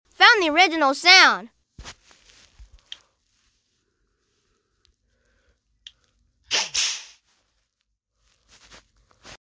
the tennis whip sound is sound effects free download